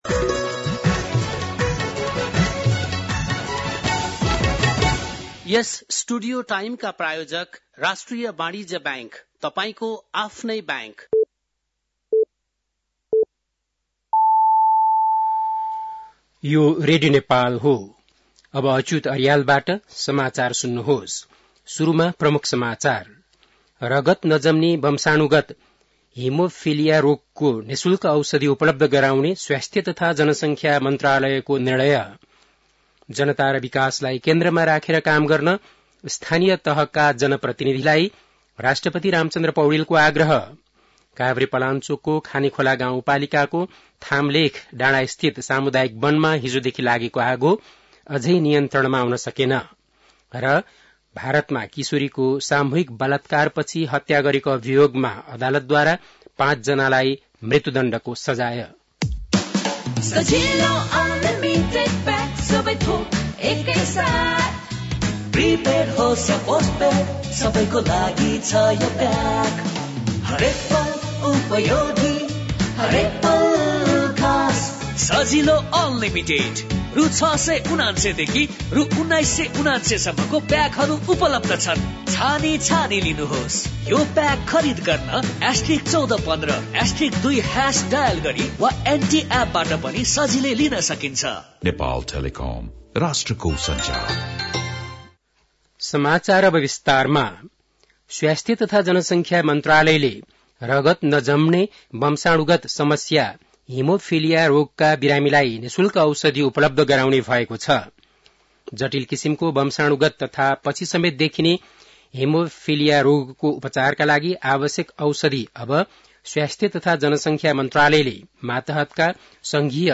बेलुकी ७ बजेको नेपाली समाचार : ११ माघ , २०८१
7-pm-nepali-news10-10.mp3